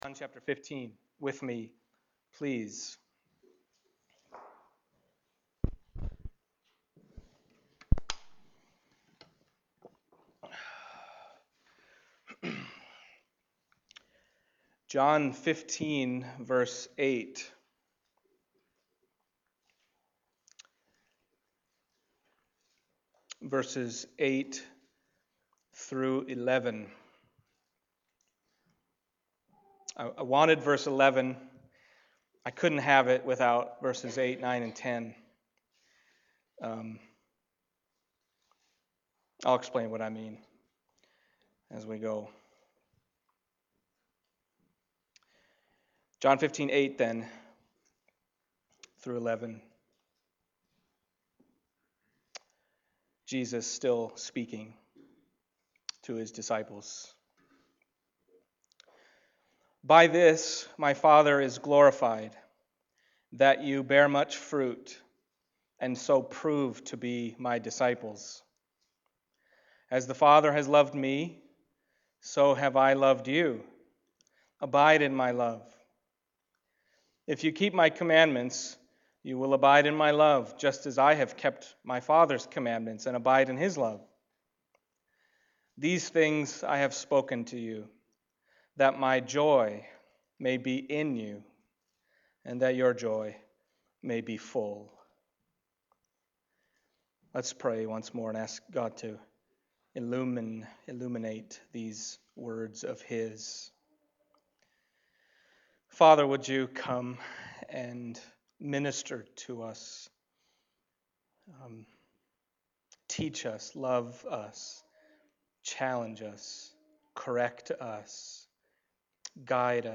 John Passage: John 15:8-11 Service Type: Sunday Morning John 15:8-11 « If My Words Abide in You…